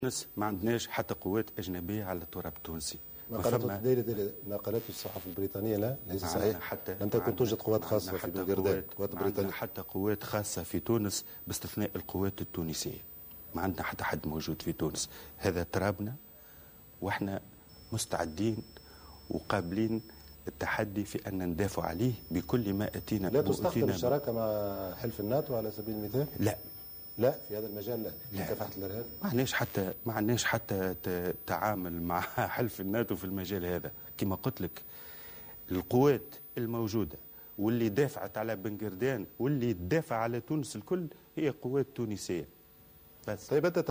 أكد وزير الداخلية هادي مجدوب، في مقابلة صحفية مع فرانس 24 اليوم الثلاثاء، ان القوات المسلحة التي دافعت عن بنقردان هي قوات تونسية خالصة.